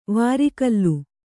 ♪ vāri kallu